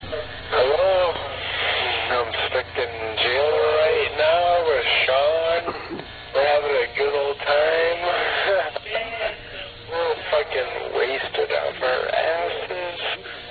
Bass, Keyboards, Vocals